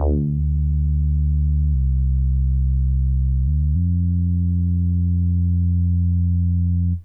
34c-bas-68Dmin.wav